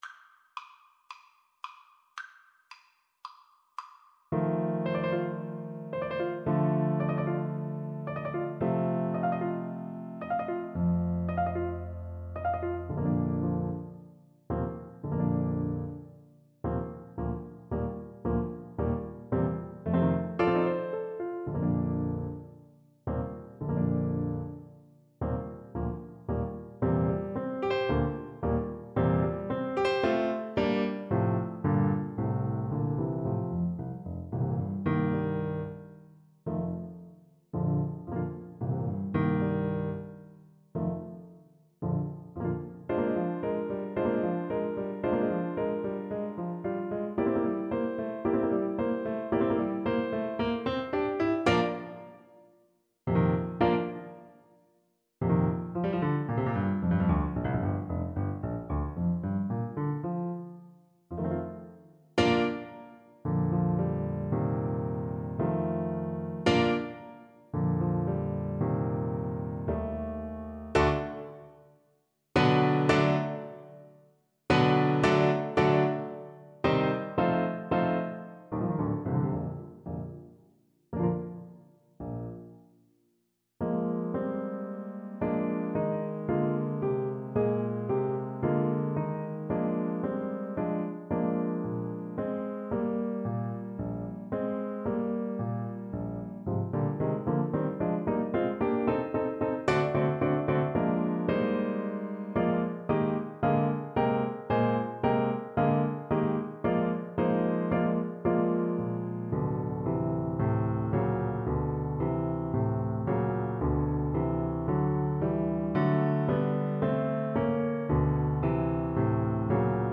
4/4 (View more 4/4 Music)
Allegretto scherzando =112
Classical (View more Classical Tenor Saxophone Music)